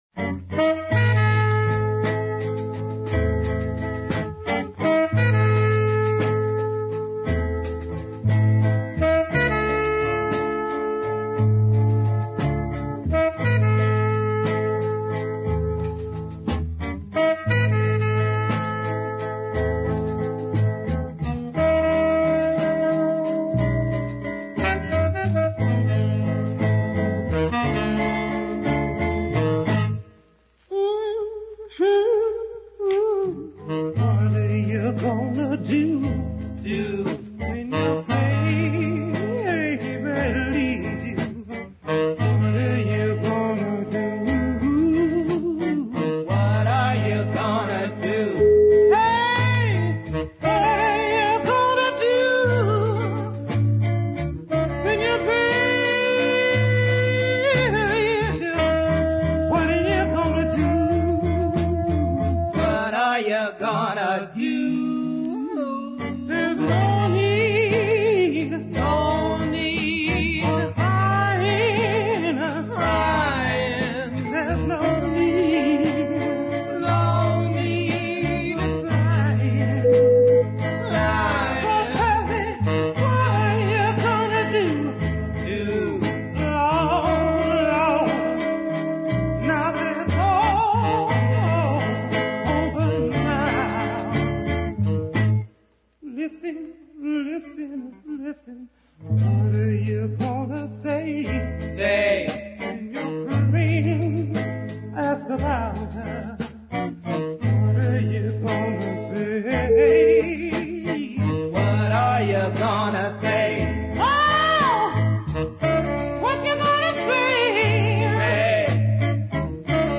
But as ever I much prefer the ballad track